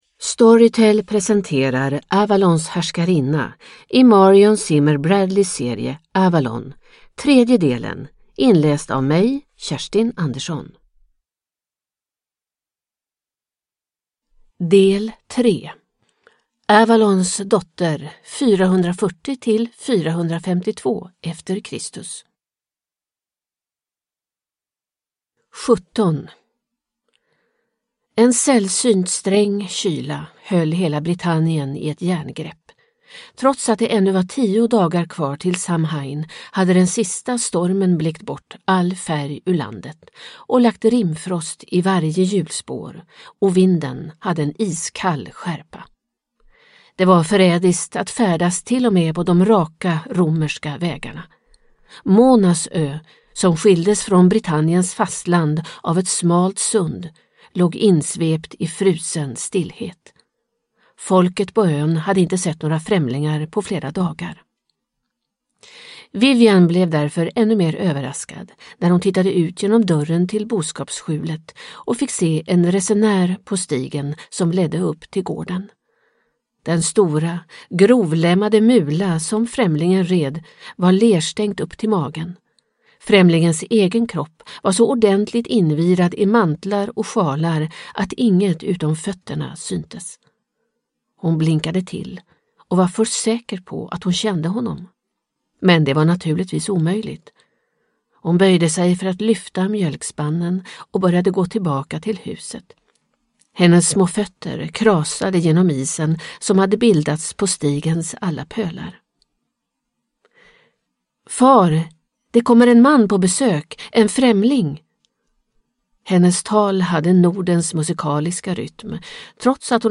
Avalons härskarinna. D. 3 – Ljudbok – Laddas ner